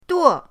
duo4.mp3